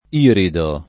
iridare v.; irido [ & rido ]